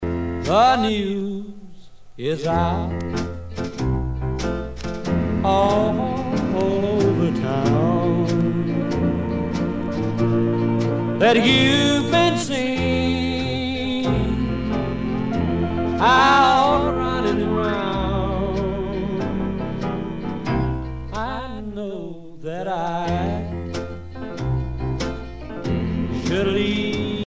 danse : slow